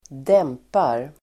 Uttal: [²d'em:par]